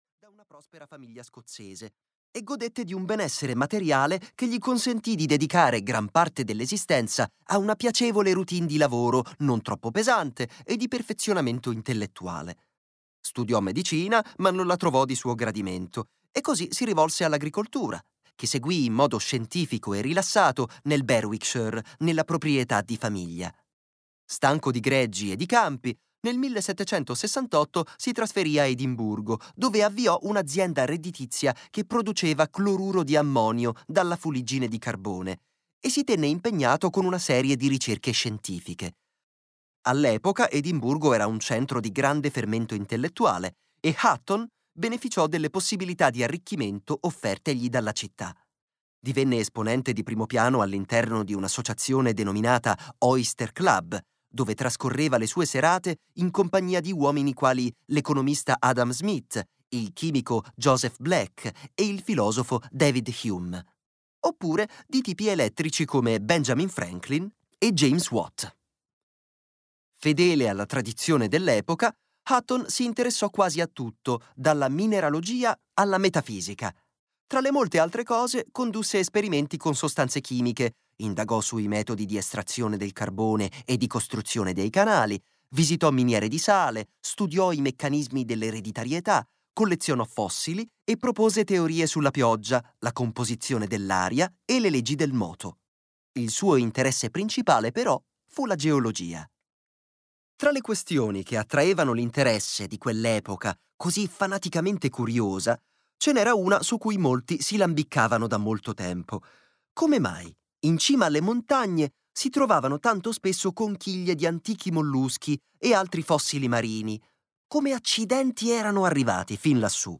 "Breve storia di (quasi) tutto" di Bill Bryson - Audiolibro digitale - AUDIOLIBRI LIQUIDI - Il Libraio